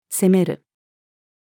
責める-female.mp3